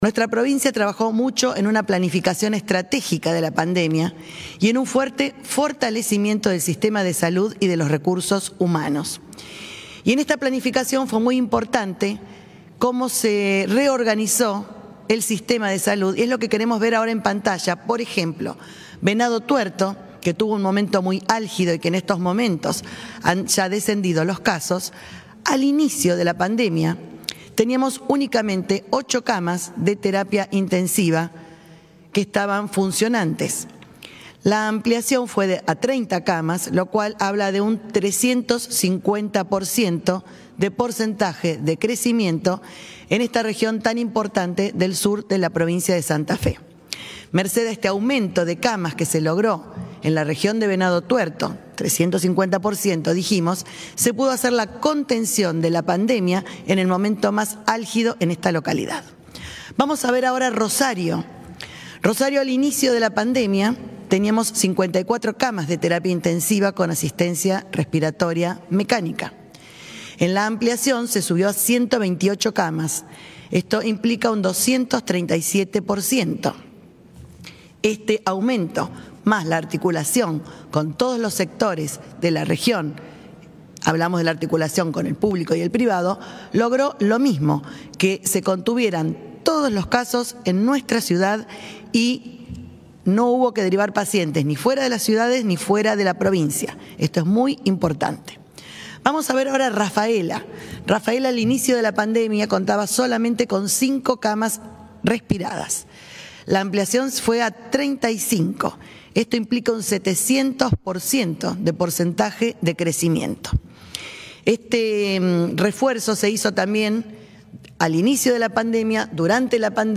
Audio informe epidemiológico a cargo de la ministra